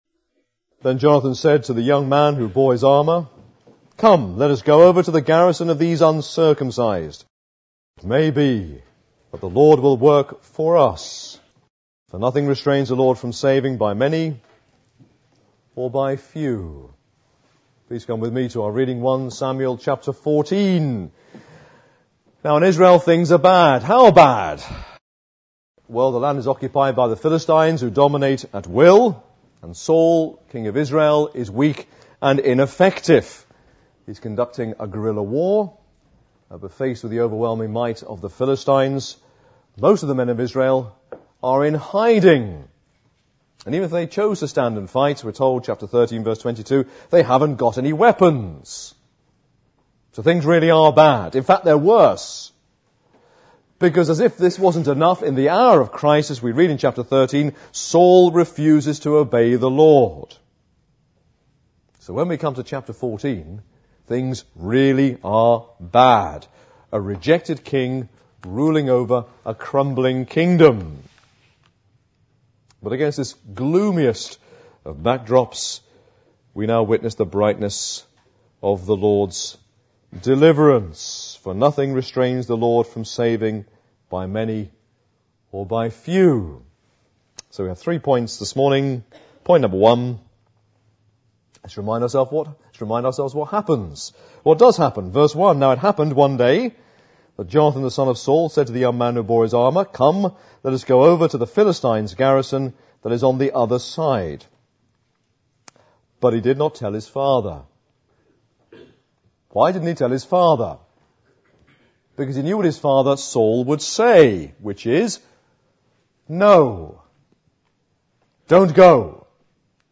APC - Sermons